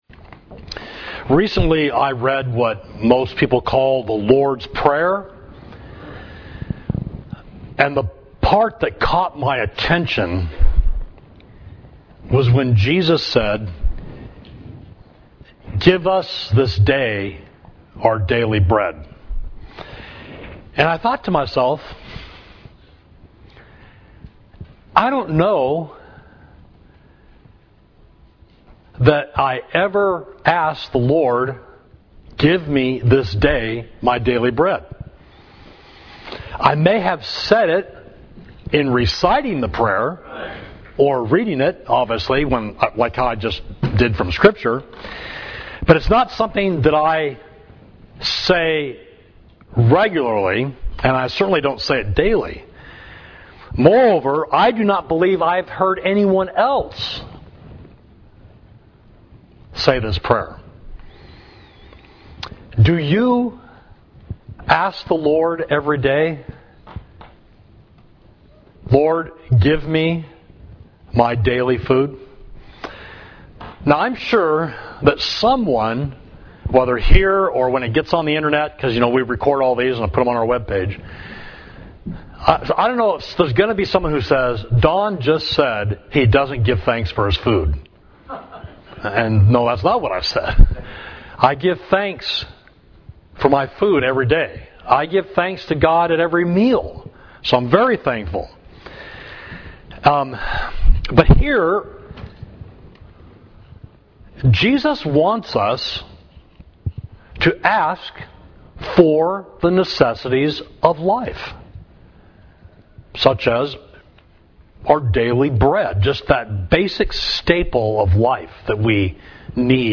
Sermon: He Gives His Beloved Sleep – Savage Street Church of Christ